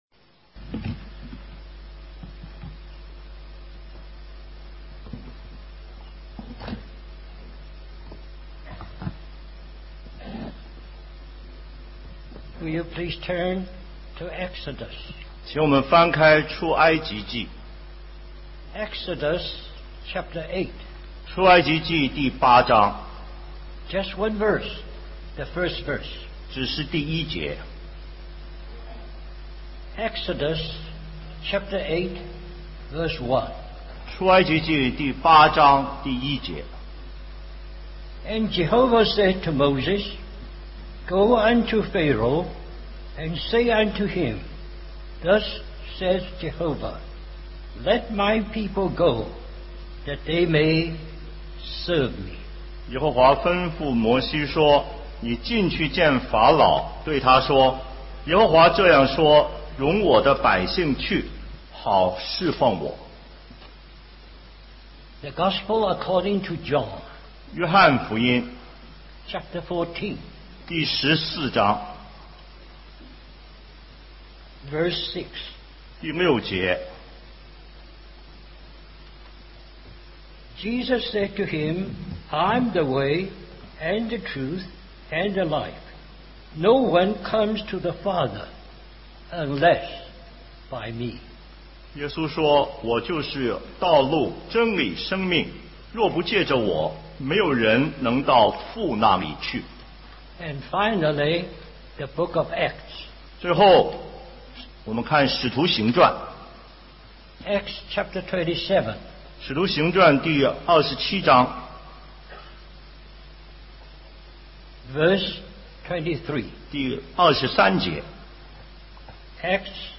2009 Special Conference For Service, Singapore Stream or download mp3 Summary Our brother shares from the conference theme of "Christ Centered Service".